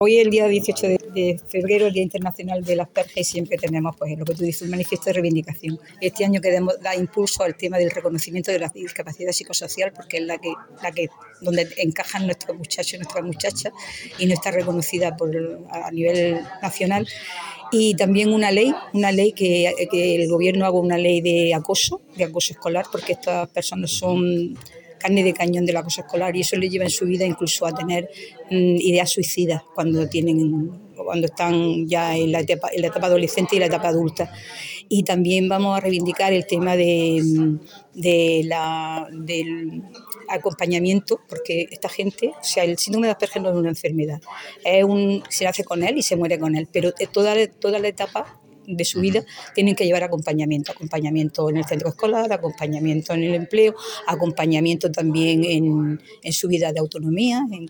La Diputación de Almería ha acogido la lectura del manifiesto de la Asociación Asperger Almería para visibilizar el síndrome por el Día Internacional del Asperger.
El vicepresidente, Ángel Escobar, participa en este acto celebrado en el Salón de Plenos, junto a la Asociación Asperger Almería, para concienciar a la sociedad